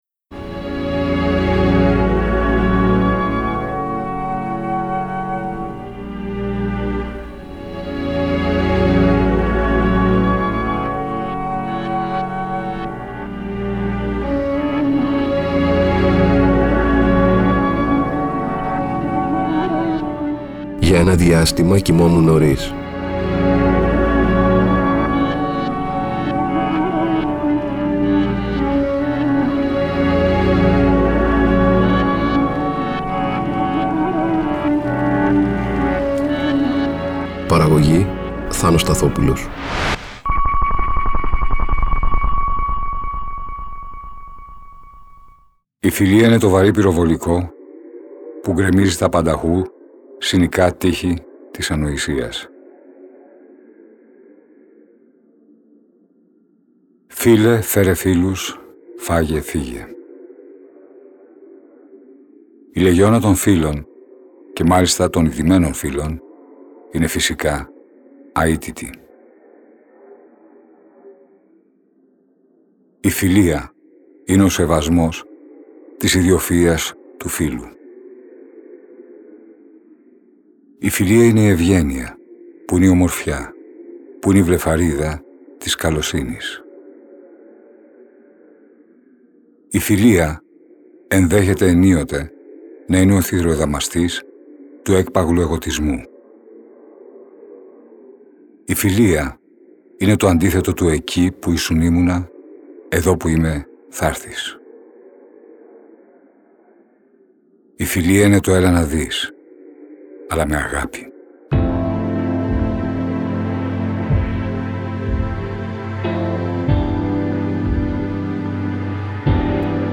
διαβάζει αποσπάσματα